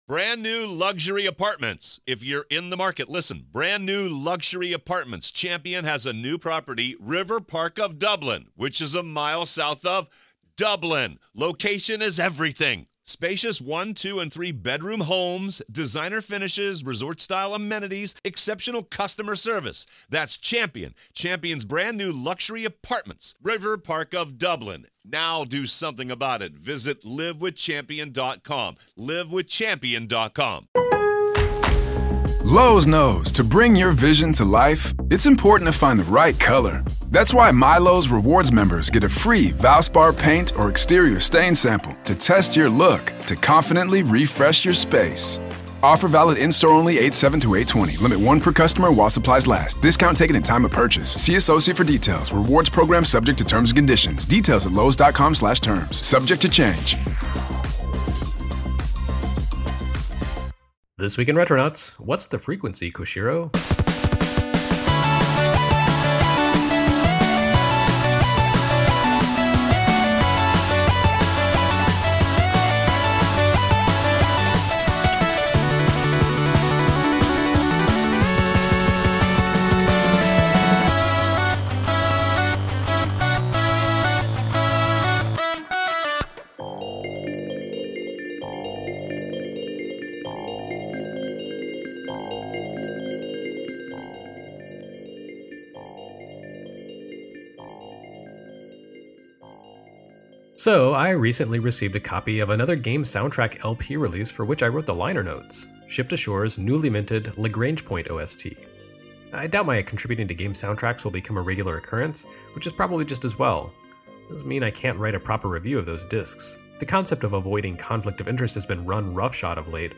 With this, the first of a series of Micro episodes focused on specific technologies and how they shape the games we love. We begin here with a podcast-appropriate aural treat: The iconic sound of FM synthesis.